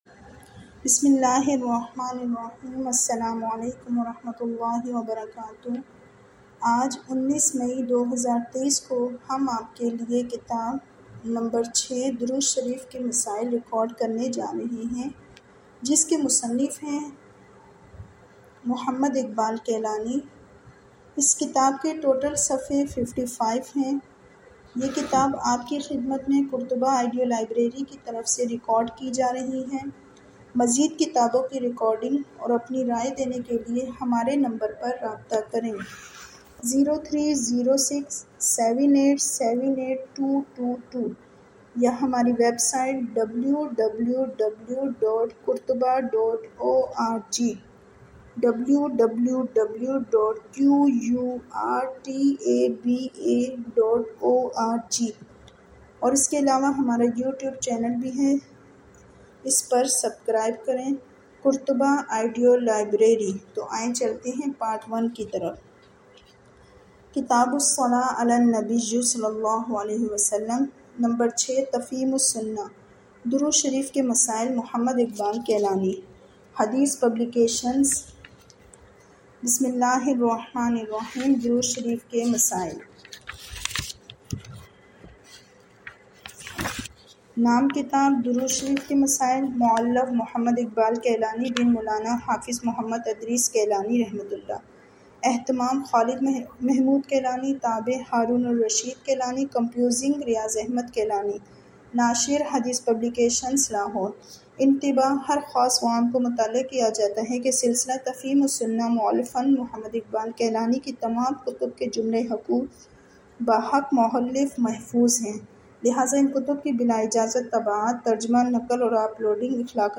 This audio book doorood shareef k masail is written by a famous author mohammad iqbal kilani and it is listed under islamic category.